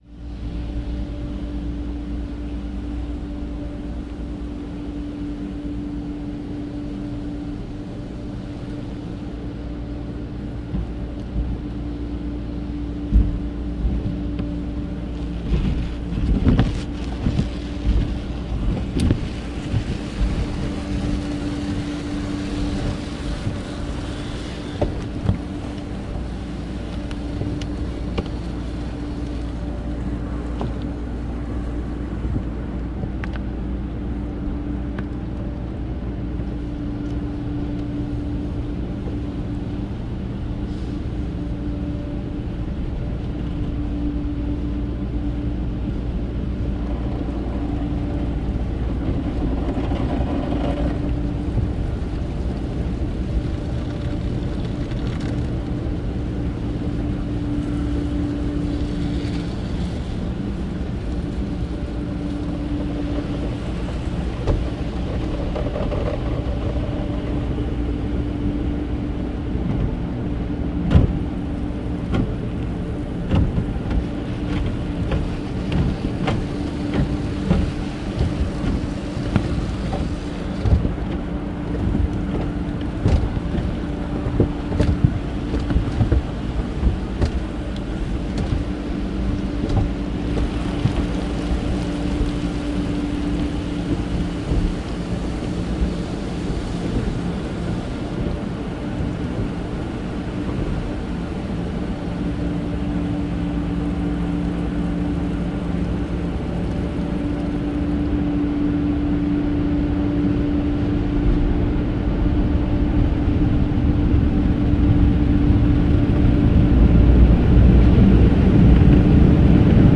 租用汽车隧道